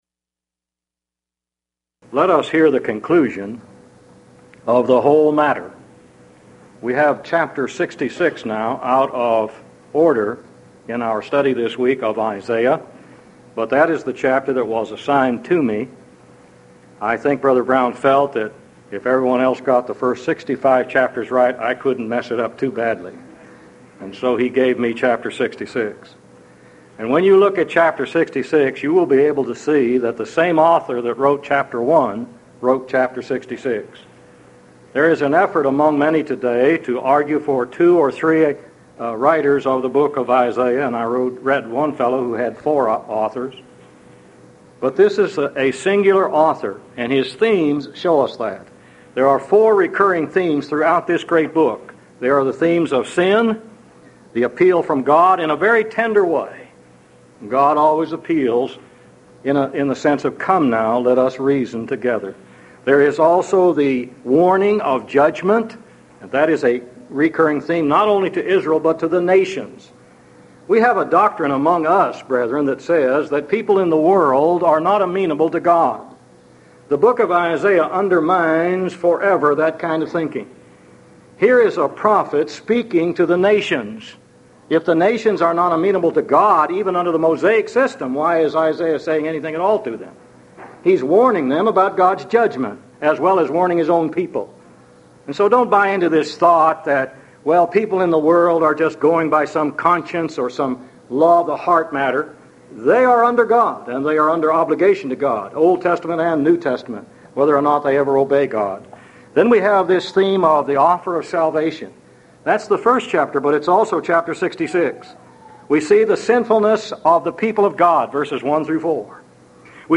Series: Houston College of the Bible Lectures Event: 1996 HCB Lectures Theme/Title: The Book Of Isaiah - Part II